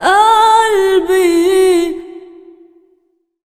SINGER B10-R.wav